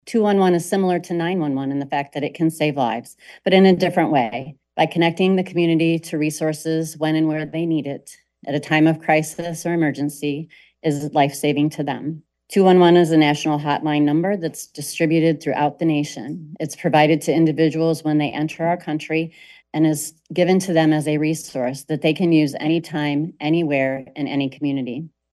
COLDWATER, MI (WTVB) – The Branch County Board of Commissioners heard a pair of requests for the 18-year allocation of opioid settlement funds during last Thursday’s work session.